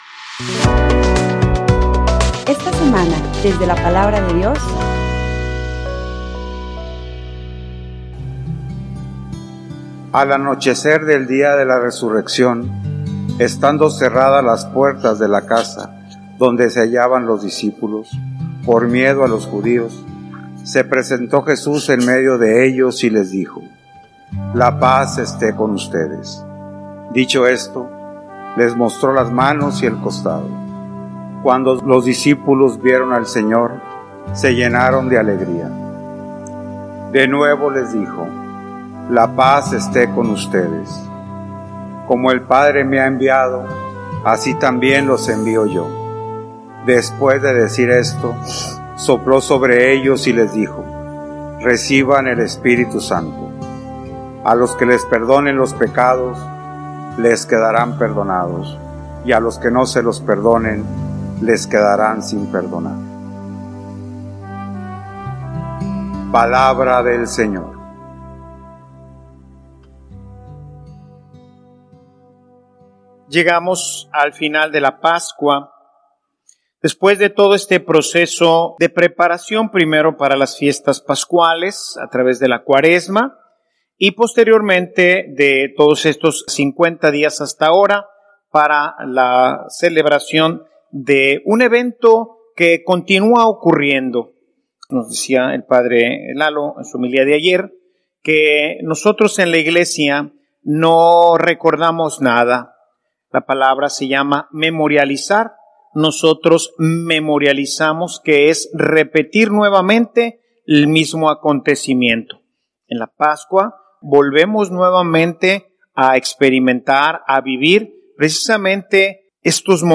homilia_Los_signos_visibles_del_Espiritu_Santo.mp3